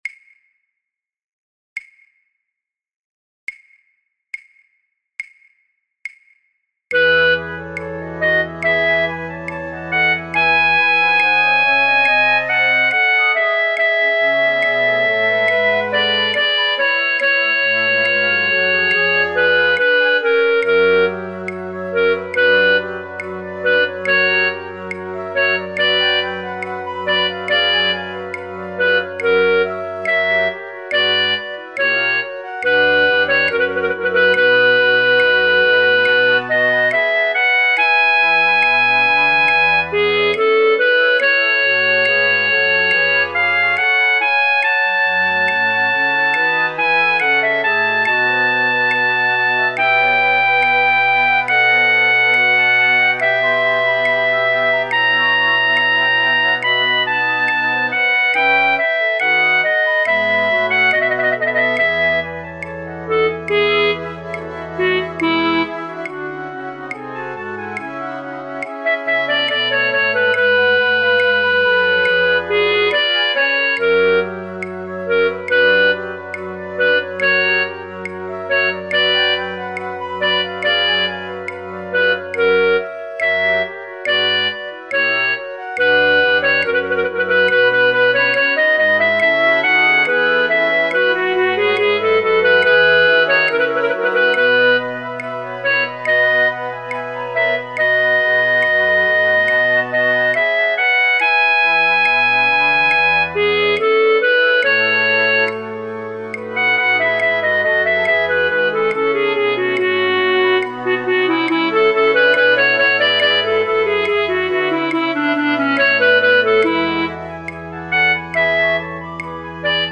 alle partijen